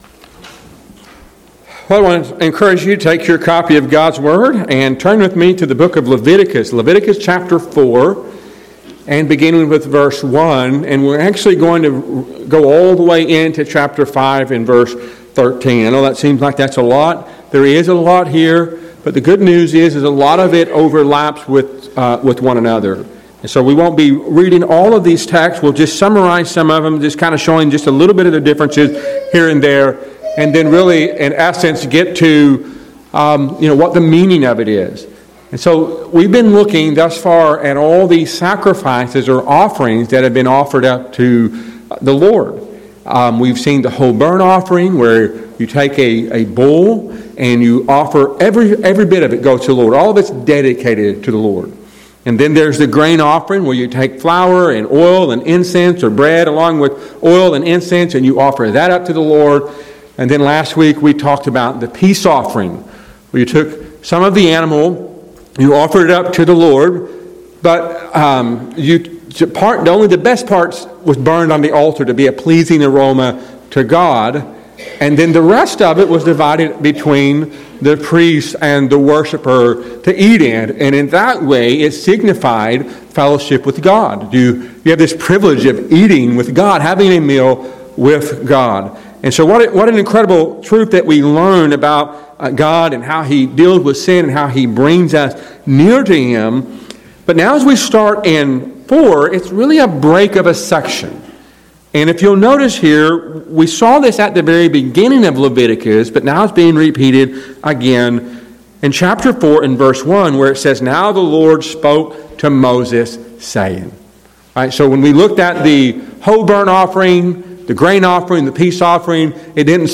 A sermon from Leviticus 4:1-5:13